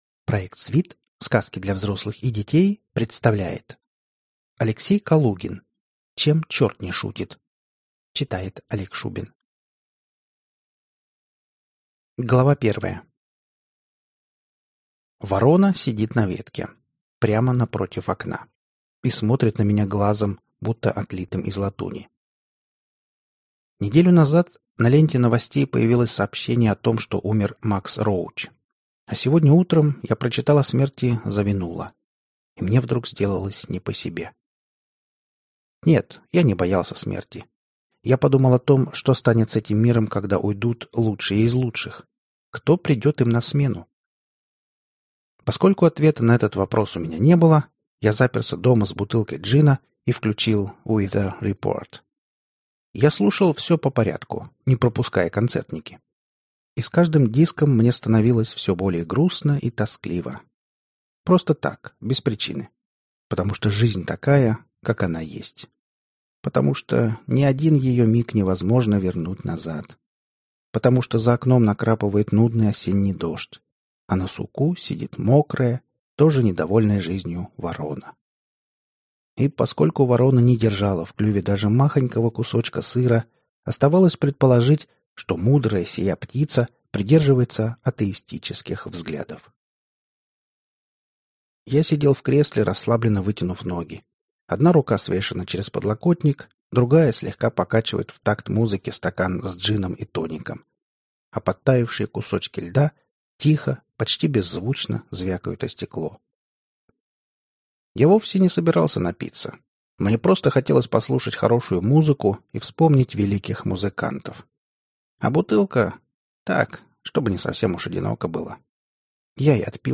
Аудиокнига Чем черт не шутит | Библиотека аудиокниг